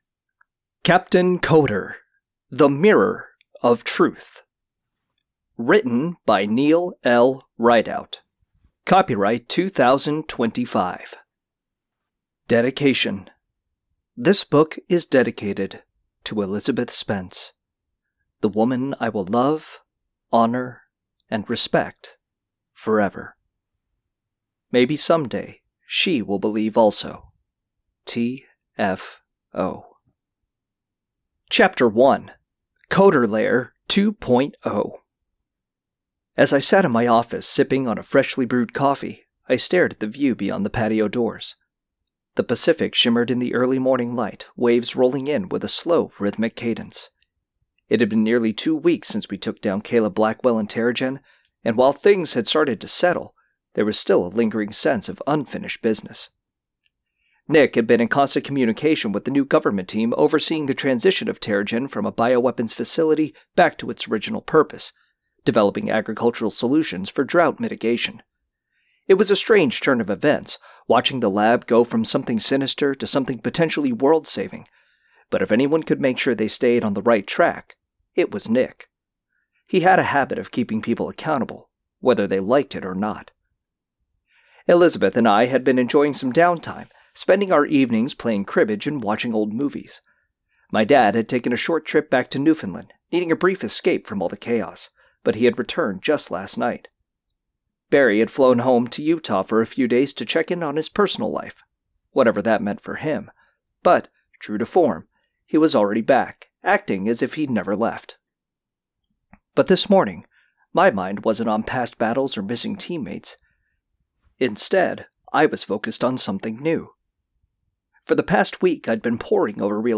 Audio Books